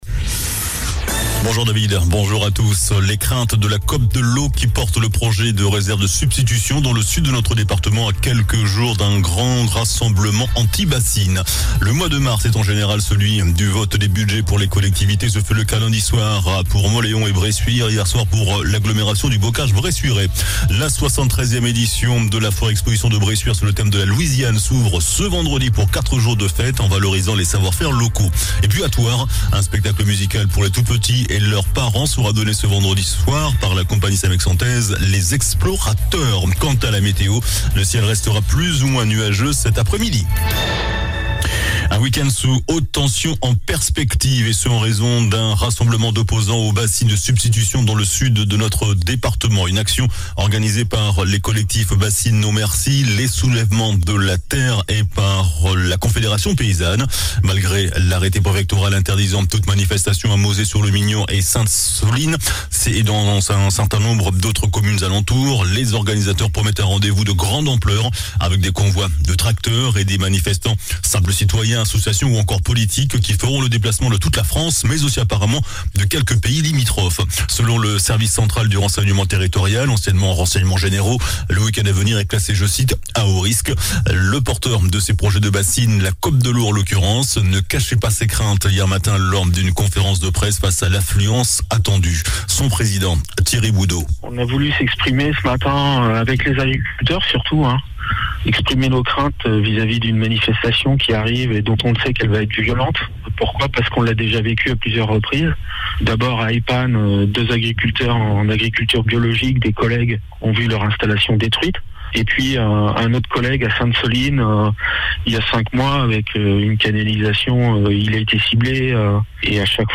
JOURNAL DU MERCREDI 22 MARS ( MIDI )